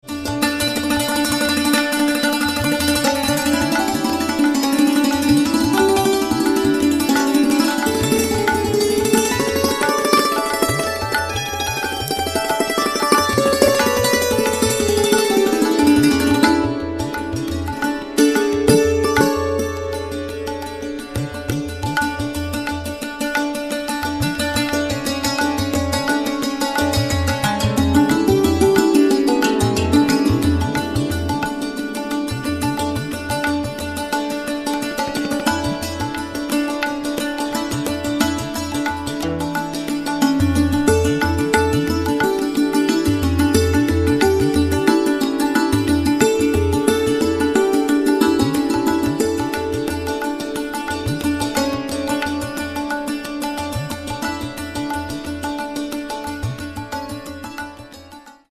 Santur
Tabla
Mittagsraga
Maddhyalaya & Drut Gats in Tintaal - 29:24
Sample aus dem Drut Gat (20:06 - 21:04) · 456 kB